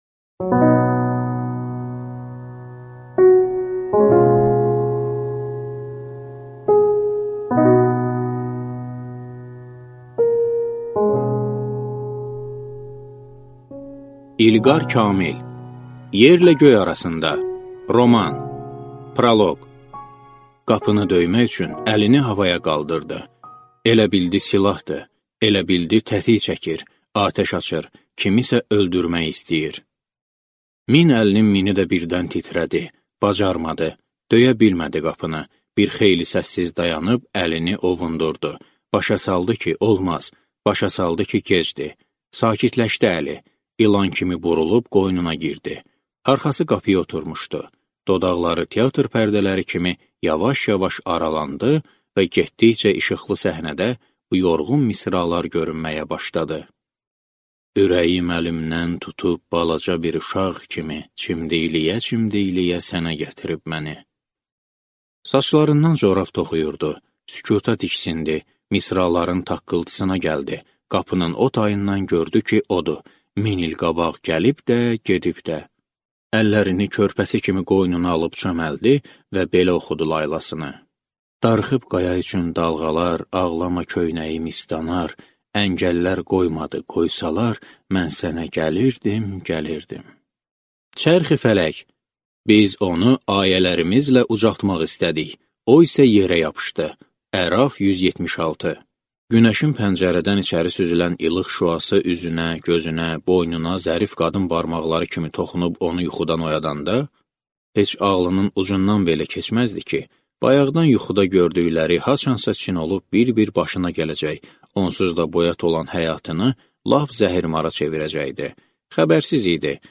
Аудиокнига Yerlə göy arasında | Библиотека аудиокниг